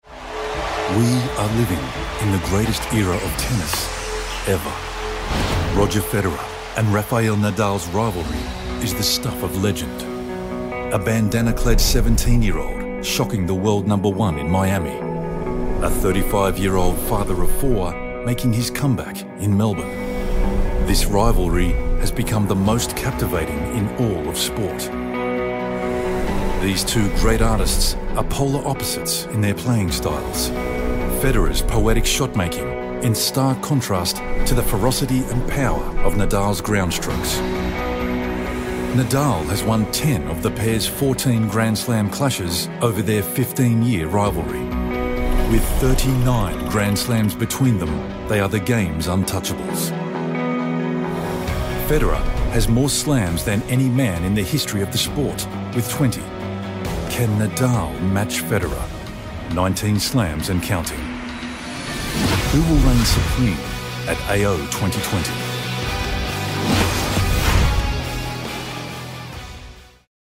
Inglês (australiano)
Documentários
BarítonoGravesContraltoProfundoBaixo